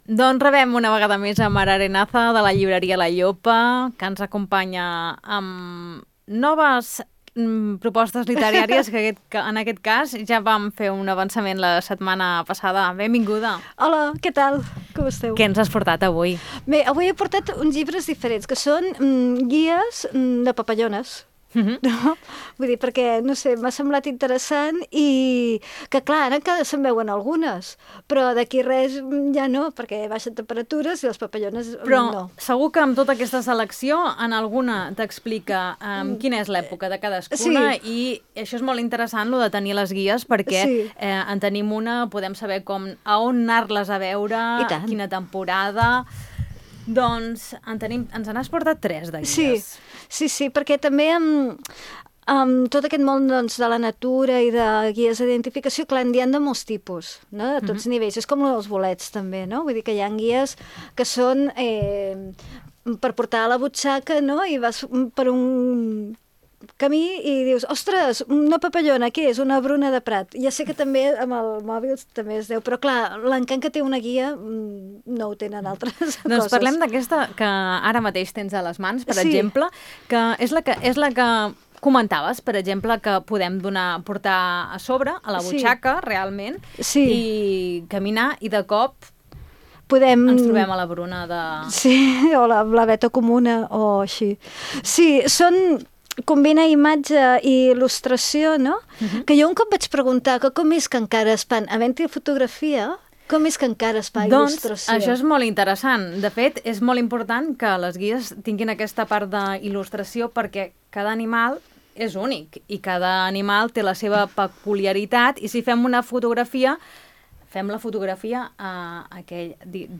A la secció de llibres d’avui d’Ona Maresme conversem